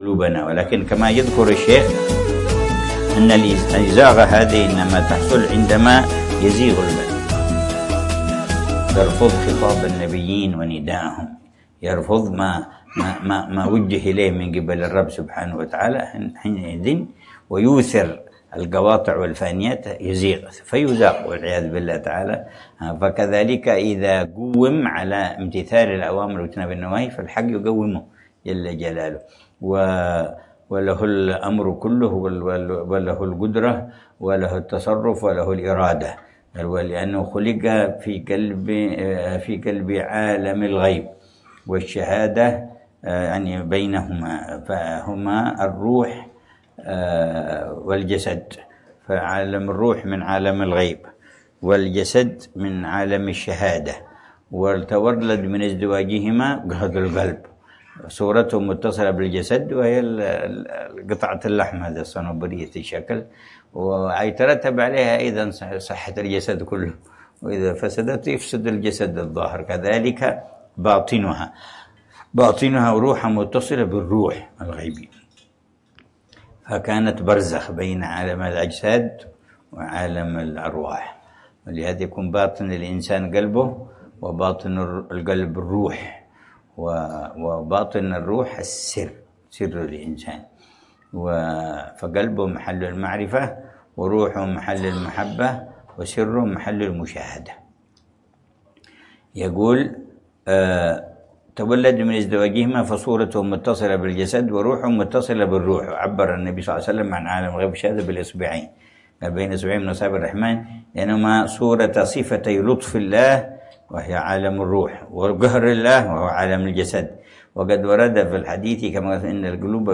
الدرس الخامس للعلامة الحبيب عمر بن محمد بن حفيظ في شرح كتاب: الكبريت الأحمر و الأكسير الأكبر في معرفة أسرار السلوك إلى ملك الملوك ، للإمام